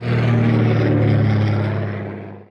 planesSlowDown.wav